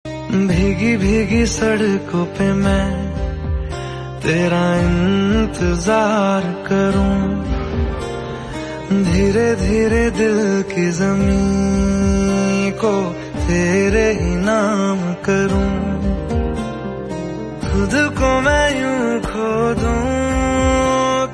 Category Bollywood